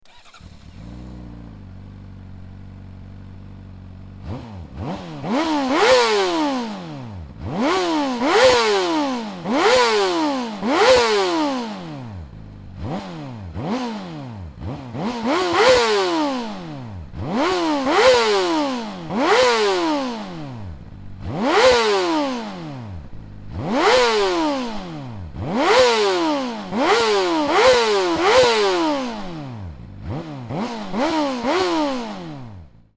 まずは排気音を収録してきたのでどうぞ・・
初期型の排気音はアイドリング時から静かな印象でしたが
2024年式ではぶっちゃけ、爆音なので